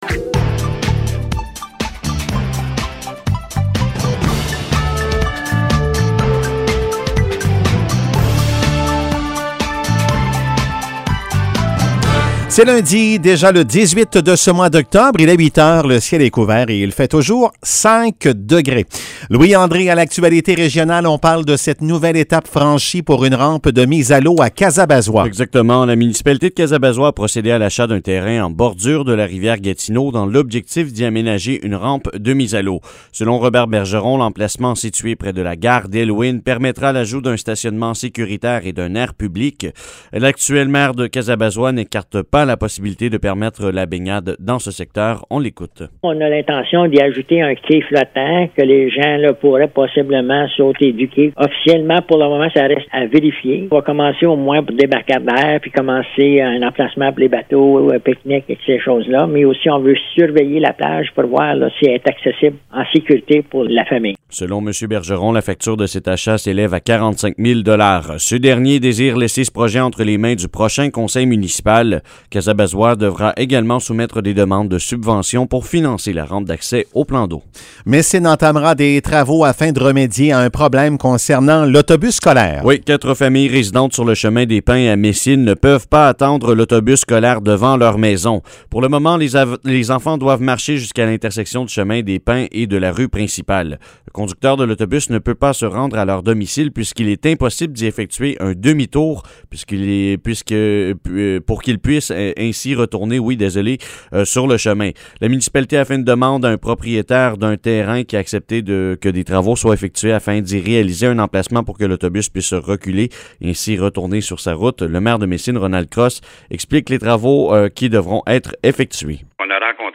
Nouvelles locales - 18 octobre 2021 - 8 h